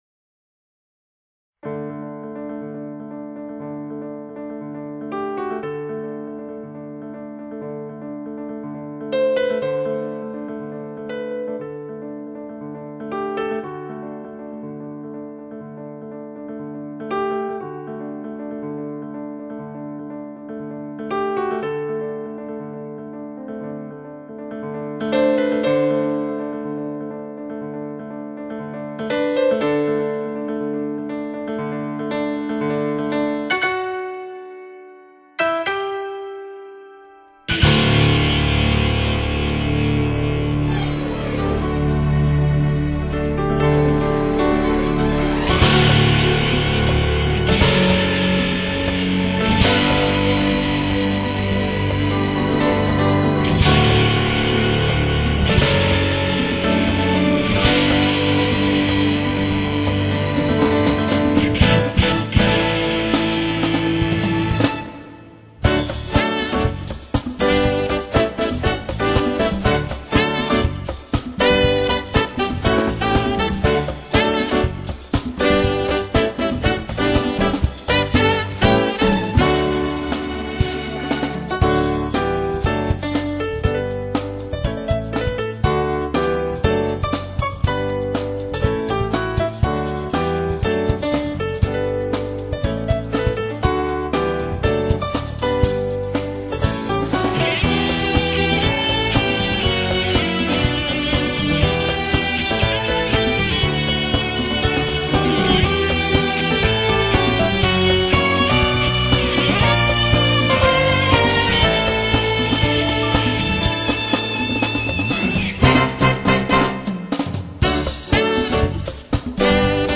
这是一张清丽美妙的jazz fusion唱片，浓郁的lounge气息直教人陶醉。
带来恢弘气势与振奋的气息。